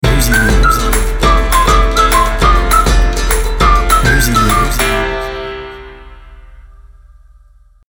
fun, nature, jeunesse, dynamique, sifflets, joie
BPM Rapide